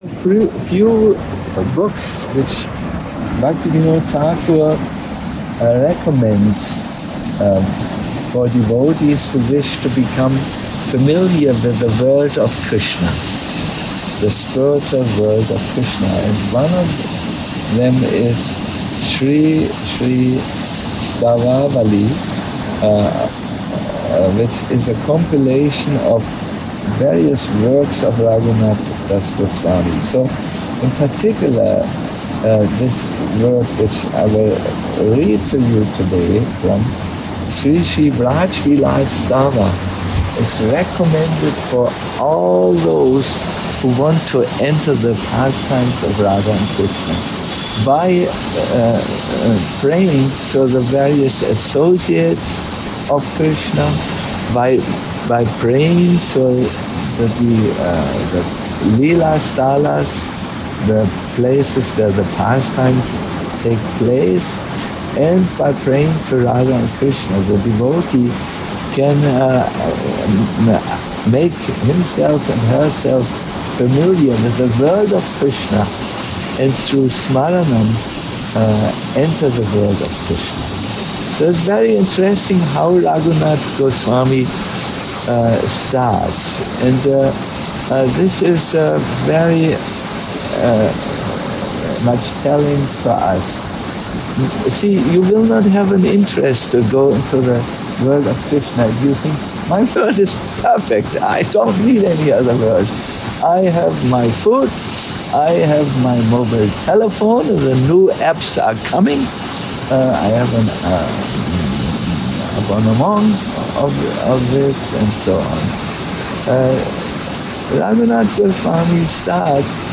Sri Vraja Vilasa Stava Katha - a lecture